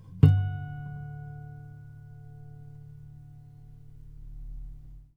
strings_harmonics
harmonic-08.wav